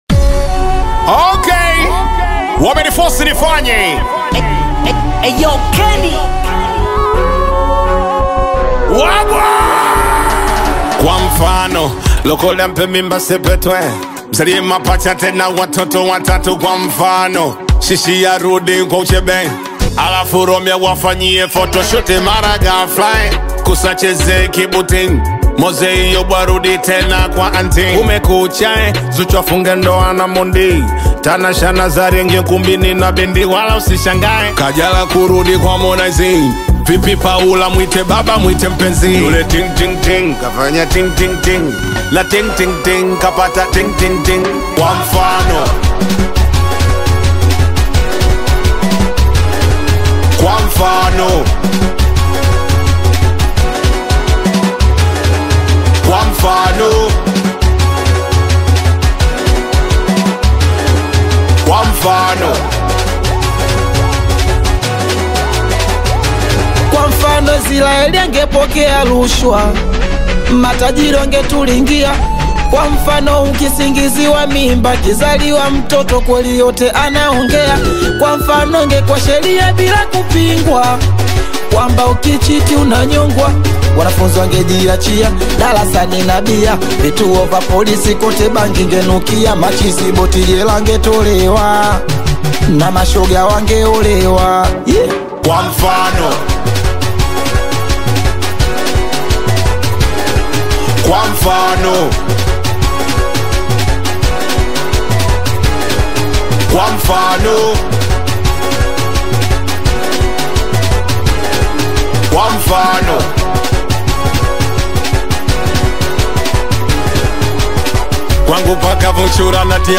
singeli
is a song that has a beautiful and interesting melody